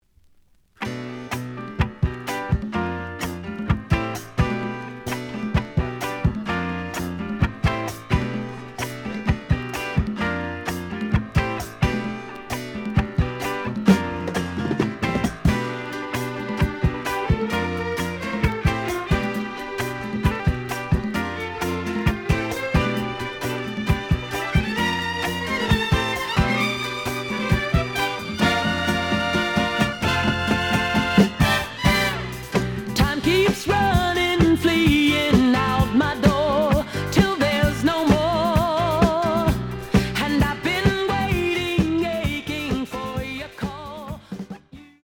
試聴は実際のレコードから録音しています。
●Genre: Disco
●Record Grading: VG+~EX- (両面のラベルに若干のダメージ。多少の傷はあるが、おおむね良好。)